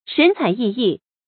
shén cǎi yì yì
神采奕奕发音
成语注音 ㄕㄣˊ ㄘㄞˇ ㄧˋ ㄧˋ
成语正音 采，不能读作“chǎi”。